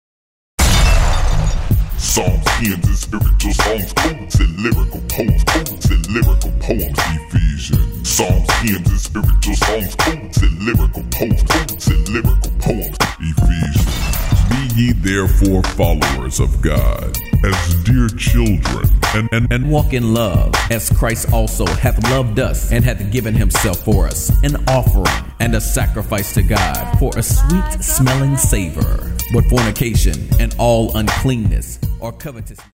An Urban Dramatized Narration of Scripture